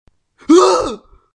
game_over.wav